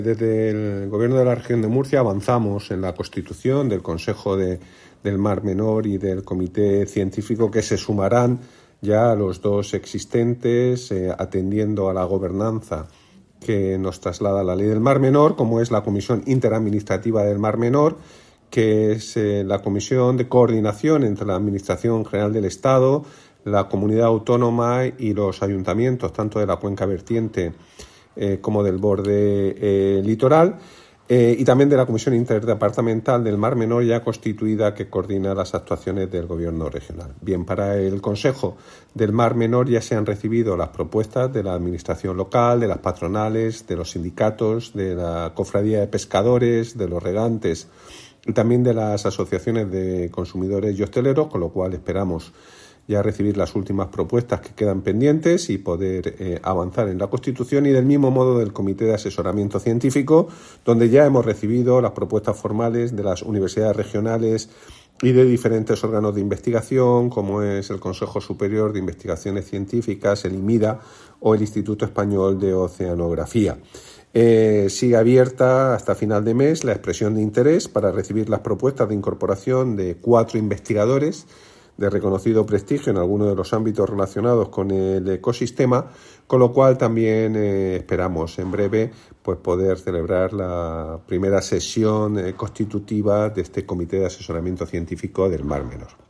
• Audio del Consejero de Medio Ambiente, Universidades, Investigación y Mar Menor, Juan María Vázquez